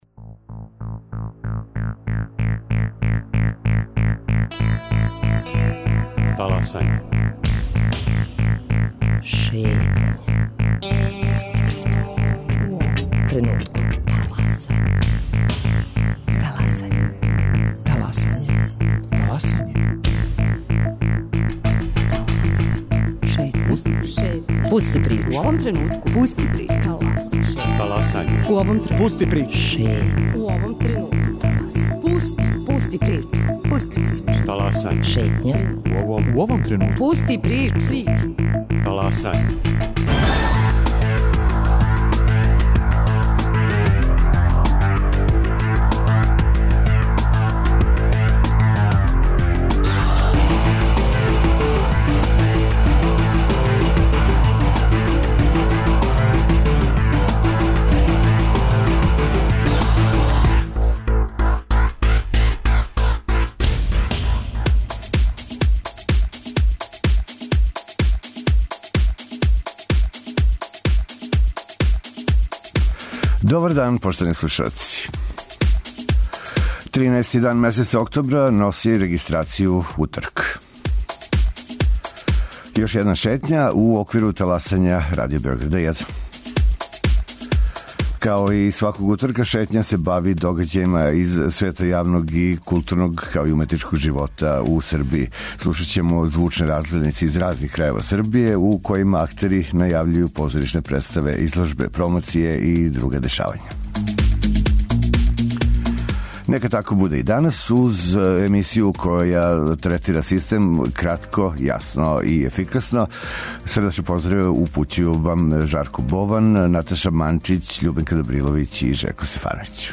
Као и сваког уторка, Шетња се бави догађајима из света јавног, културног и уметничког живота у Србији. Звучне 'разгледнице' из разних крајева Србије, у којима актери најављују позоришне представе, изложбе, промоције и друга дешавања.